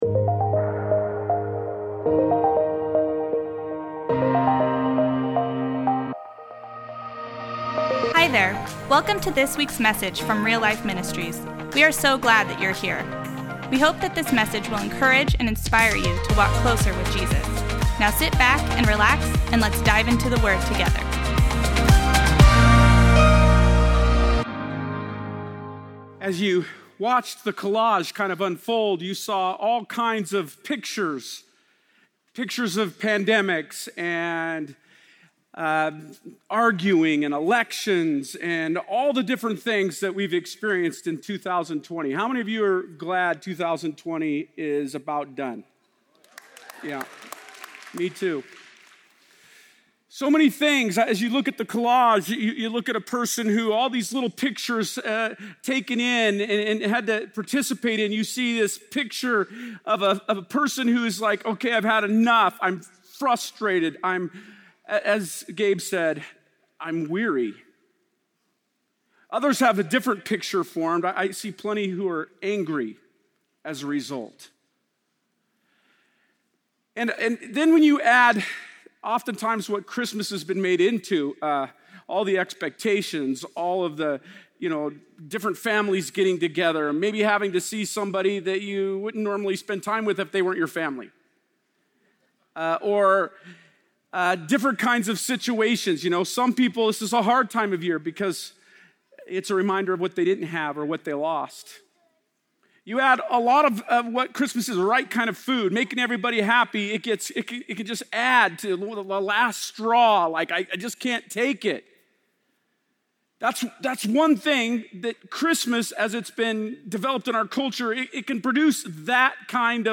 Christmas Eve Service – PF Campus